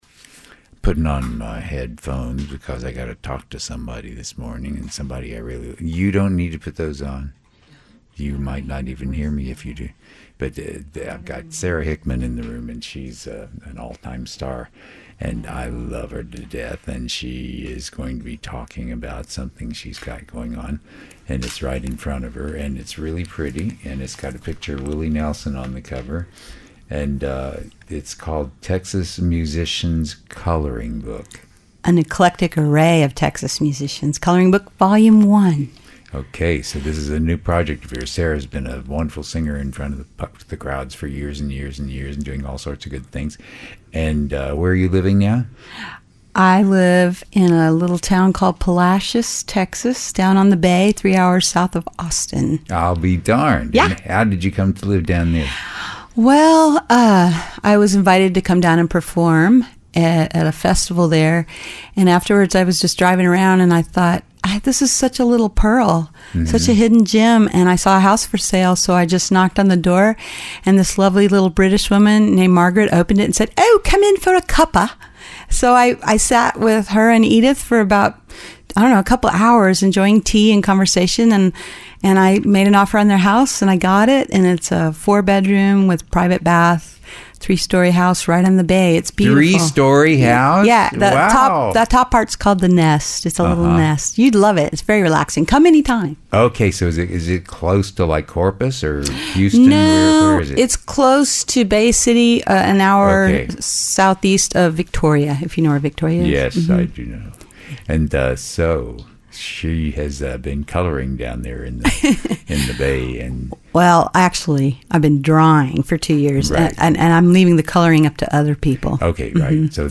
Eklektikos Interview